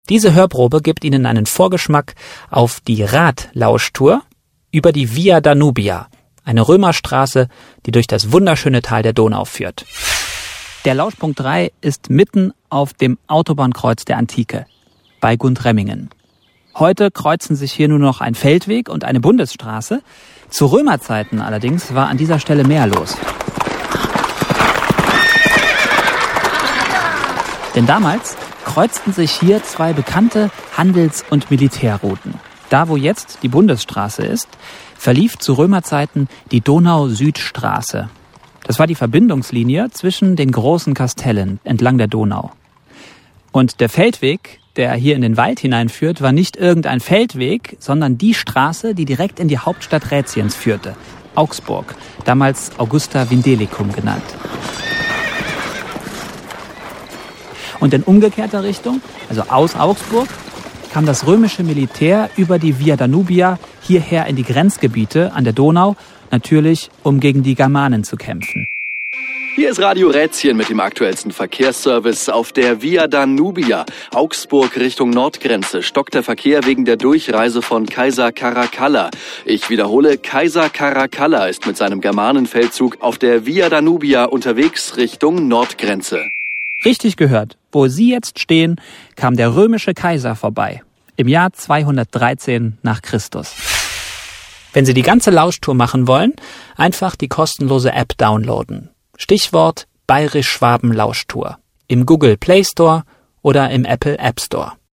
Diese Radtour mit Audioguide versetzt Sie zurück in die Zeit der Römer.